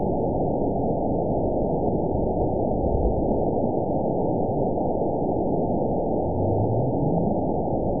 event 920456 date 03/26/24 time 14:45:28 GMT (1 year, 1 month ago) score 9.30 location TSS-AB02 detected by nrw target species NRW annotations +NRW Spectrogram: Frequency (kHz) vs. Time (s) audio not available .wav